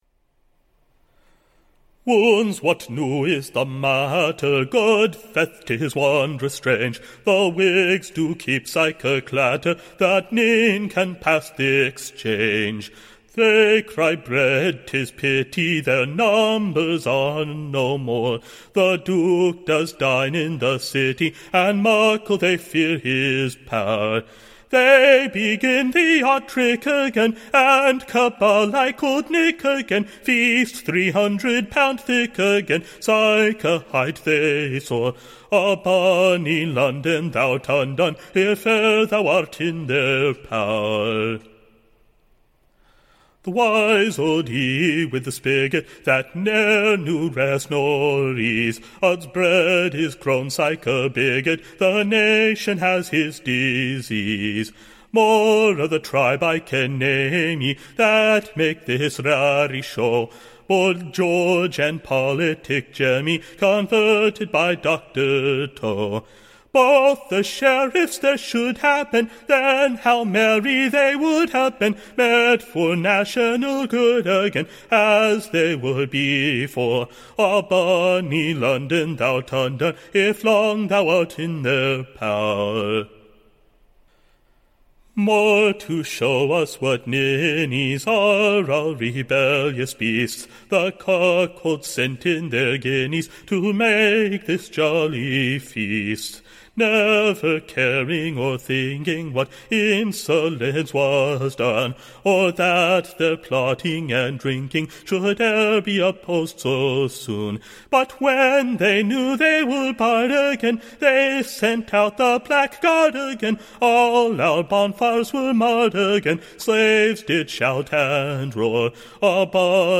Recording Information Ballad Title The Whigg-Feast: / A SCOTCH BALLAD, Tune Imprint made to the Tune / of a new and pleasant Scotch Dance.